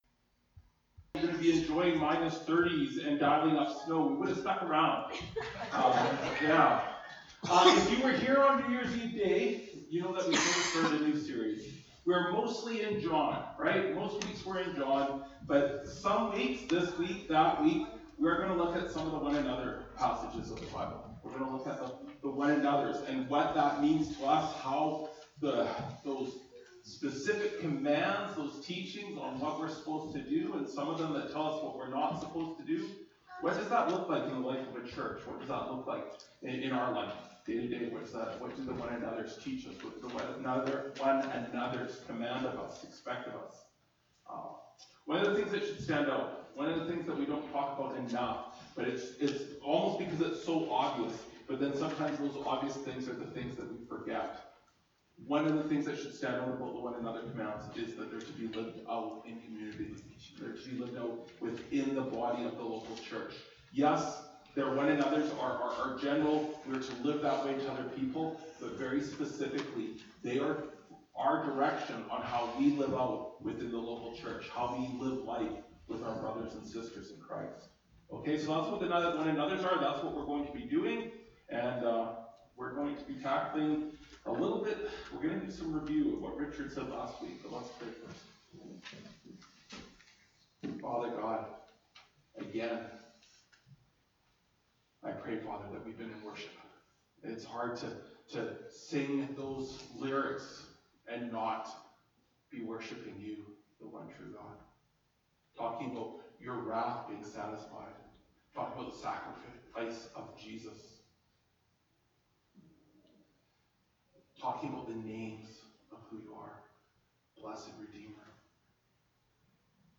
Jan 28, 2024 Love One Another (John 13:21-38) MP3 SUBSCRIBE on iTunes(Podcast) Notes Discussion Sermons in this Series This sermon was recorded at Grace Church - Salmon Arm and preached in both Salmon Arm and Enderby.